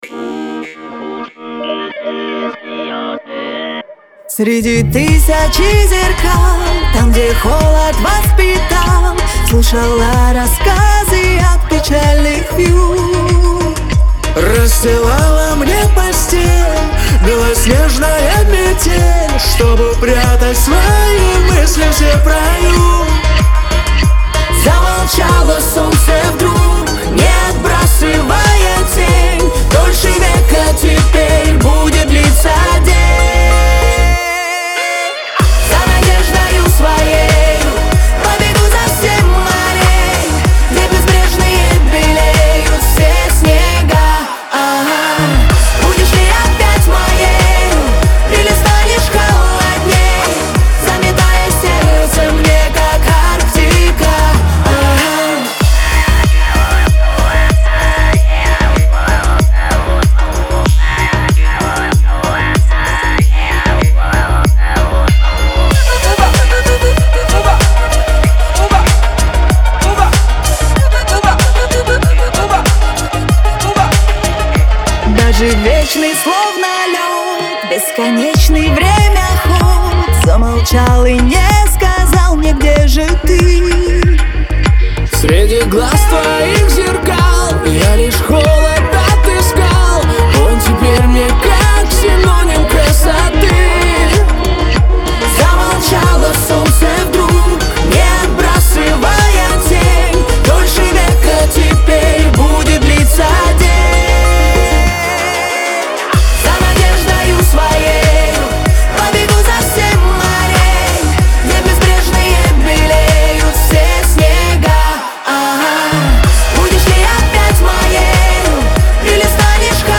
Лирика , эстрада
ансамбль , диско